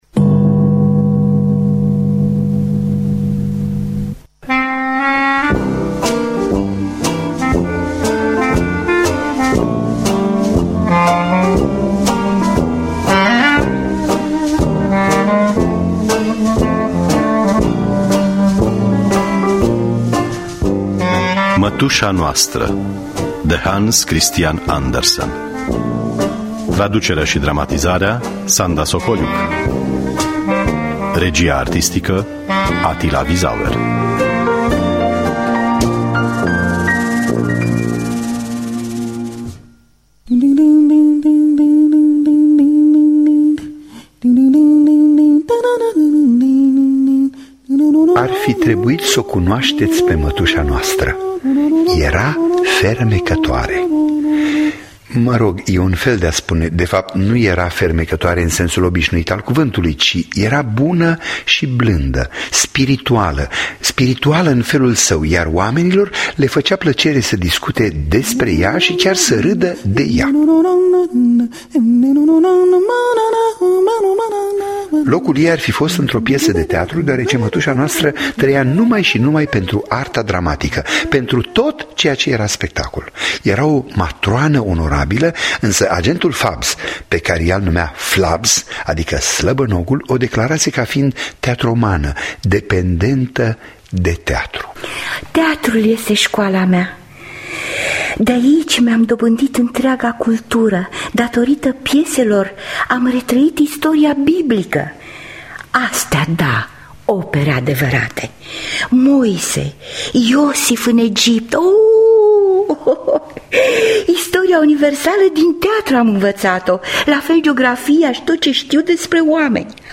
Mătuşa noastră de Hans Christian Andersen – Teatru Radiofonic Online
Traducerea şi dramatizarea radiofonică